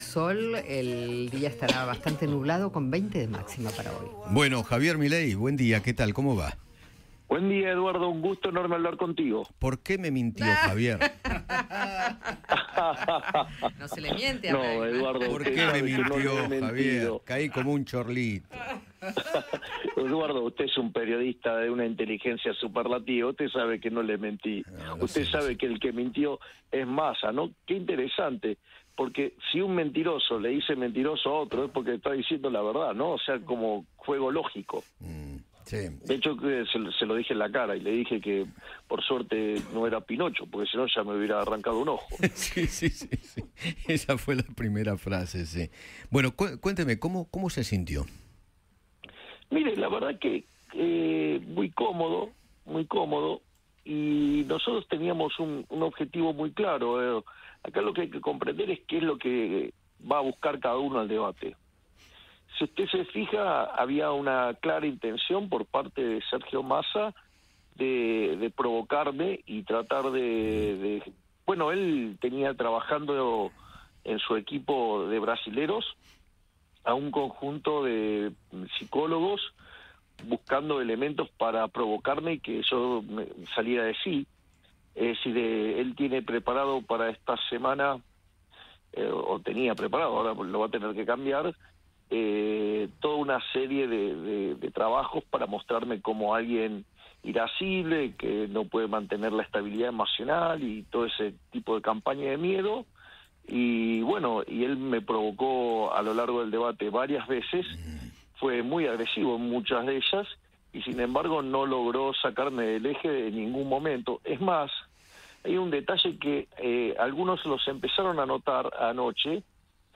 Eduardo Feinmann conversó con el candidato por La Libertad Avanza sobre el tercer Debate Presidencial.